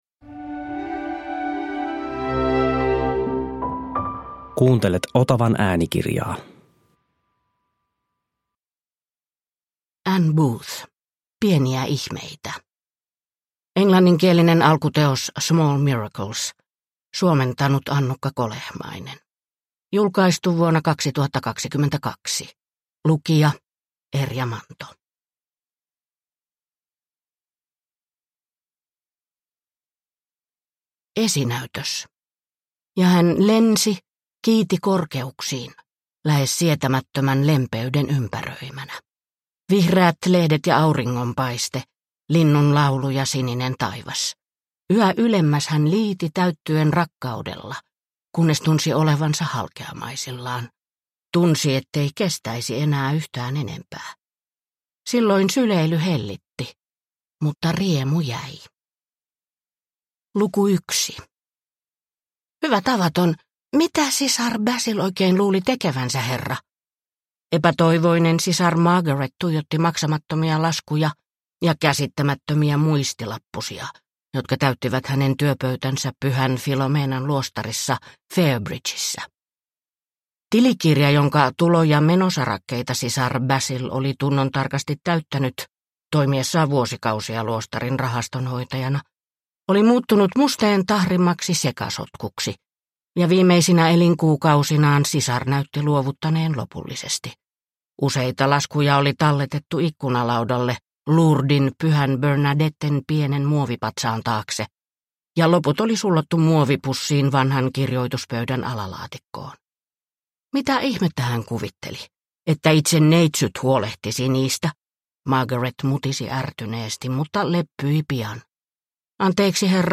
Pieniä ihmeitä – Ljudbok